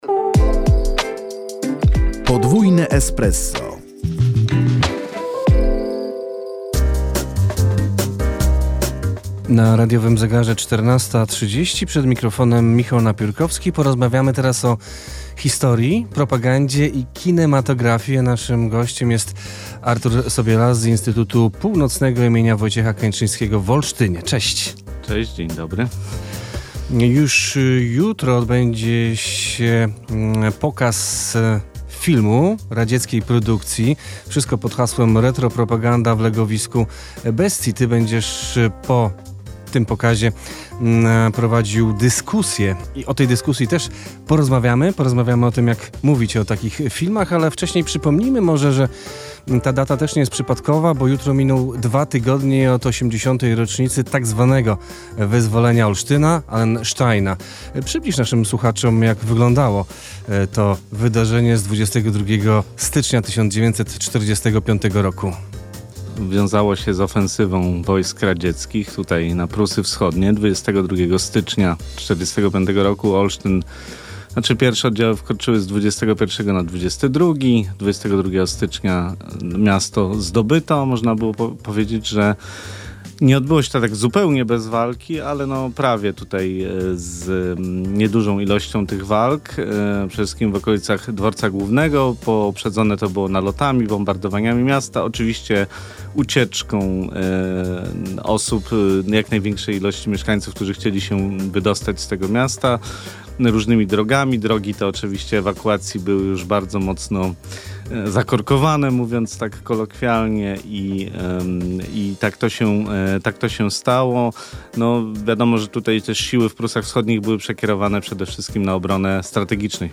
Więcej o propagandzie w filmie, w rozmowie